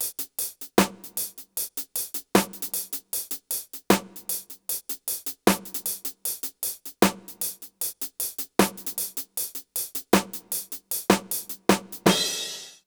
British REGGAE Loop 080BPM (NO KICK).wav